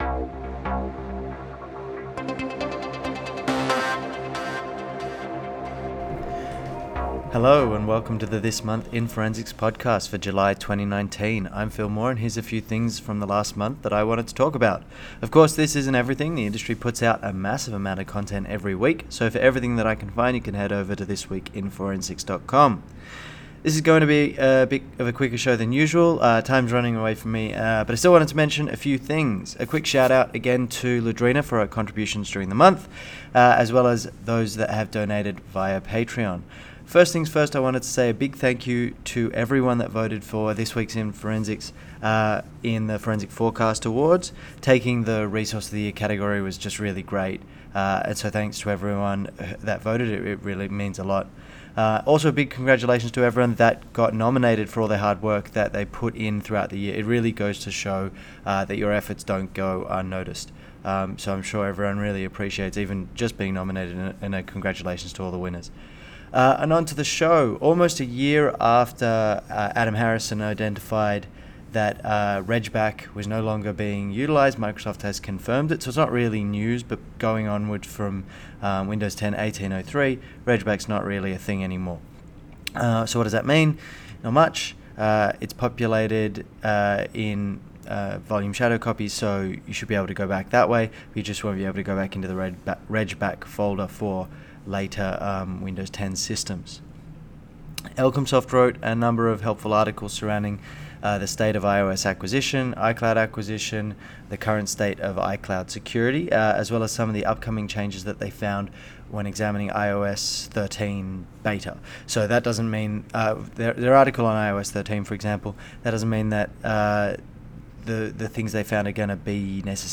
*Apologies for the name pronunciations, ums and ahs, and general production quality 🙂